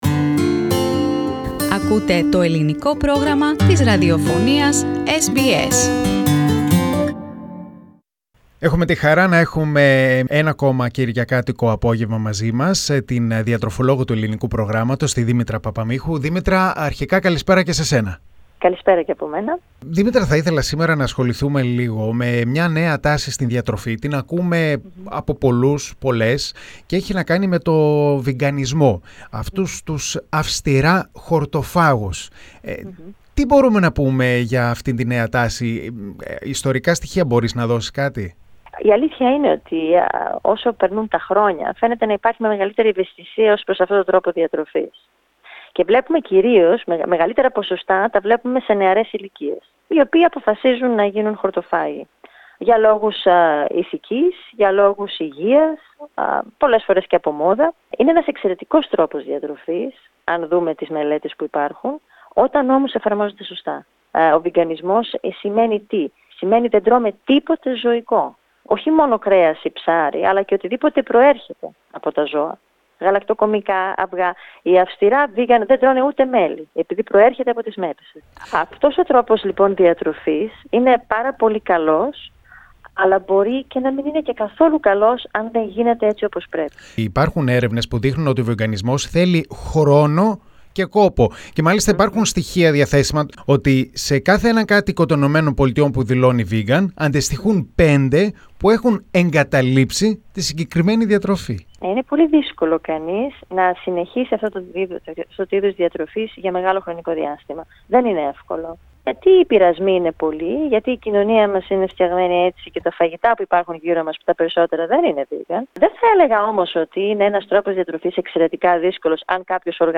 The interview